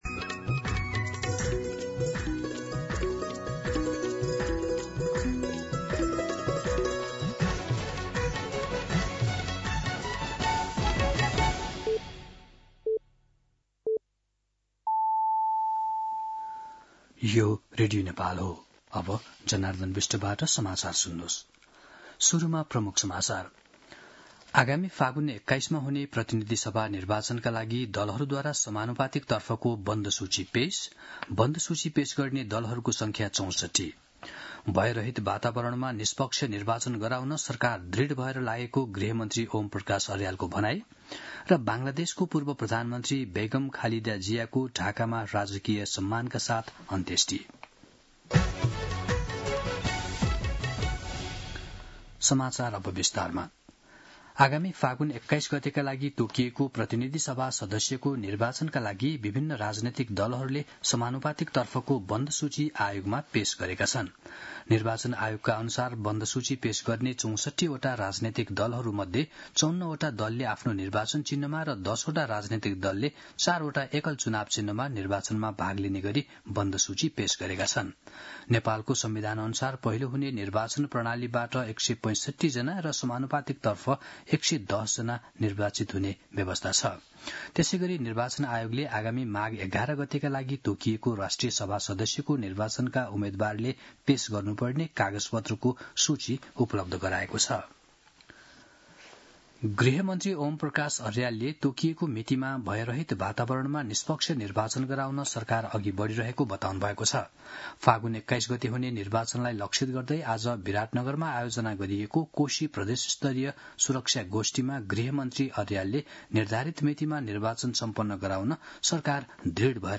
दिउँसो ३ बजेको नेपाली समाचार : १६ पुष , २०८२
3-pm-news-9-16.mp3